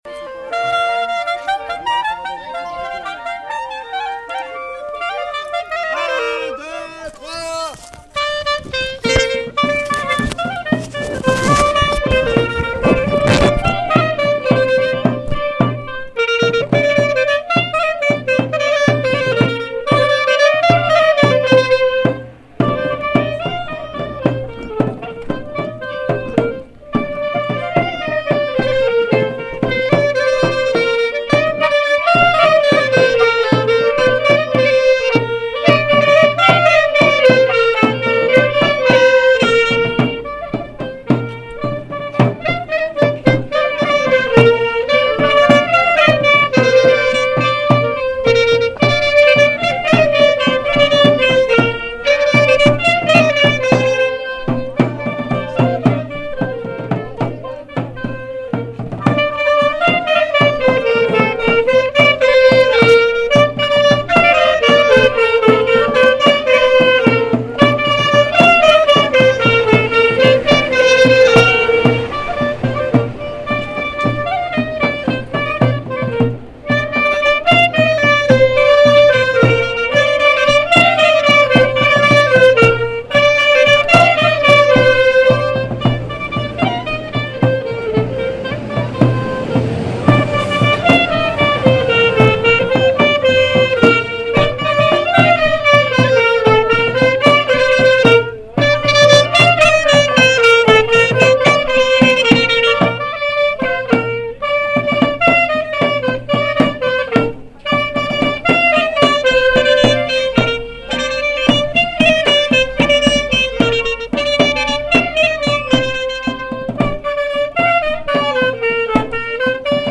01_marche1-clarinettes.mp3